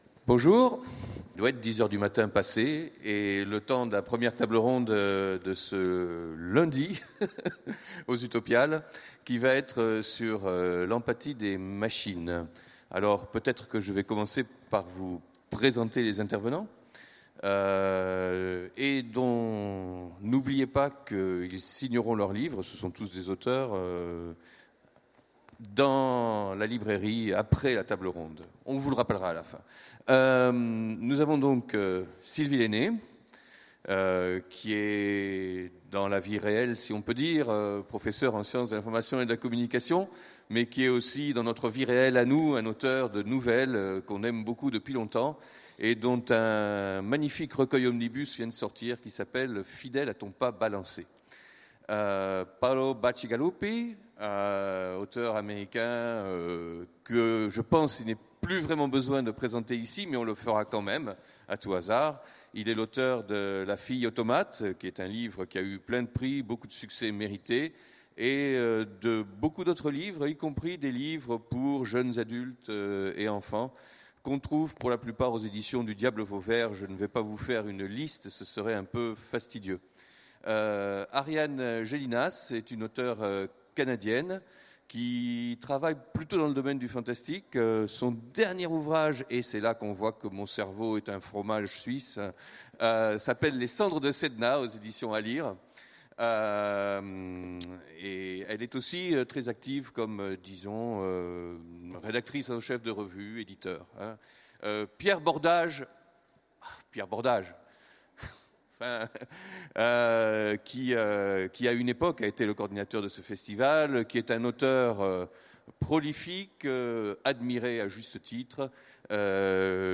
Utopiales 2016 : Conférence L’empathie avec les machines